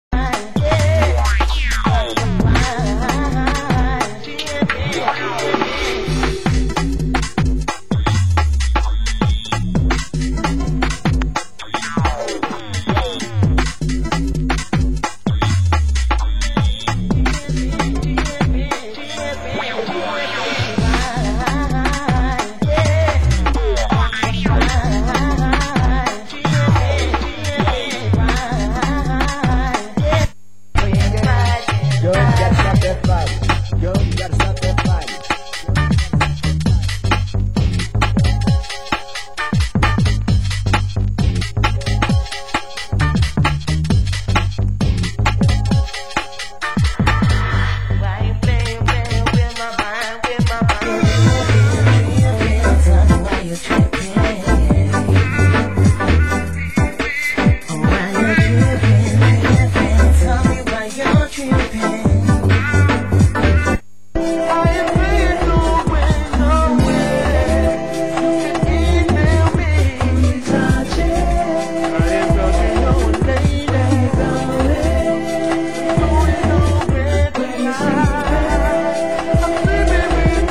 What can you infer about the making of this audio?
Format: Vinyl 12 Inch